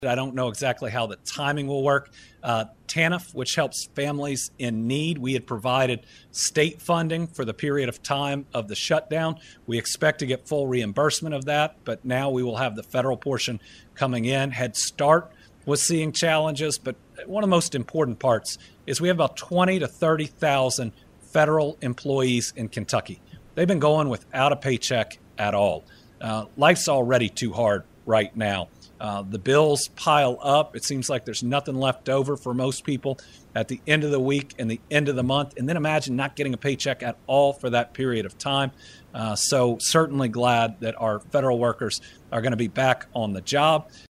Governor Andy Beshear addressed the impact of the recent federal government shutdown and updates on SNAP benefits during his Team Kentucky Update on Thursday.